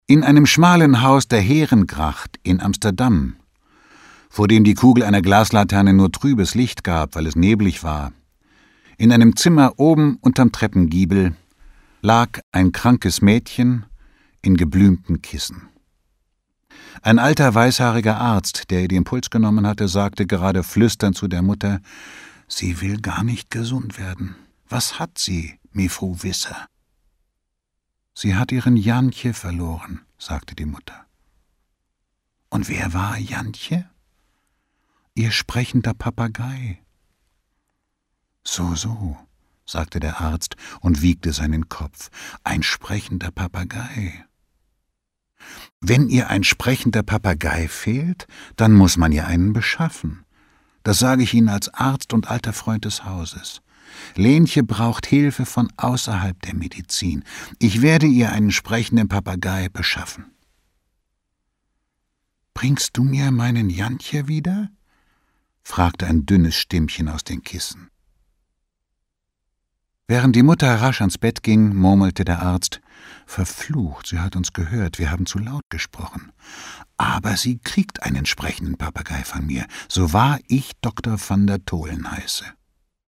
Der Weihnachtspapagei 1 CD James Krüss (Autor) Walter Kreye (Sprecher) Audio-CD 2007 | 2.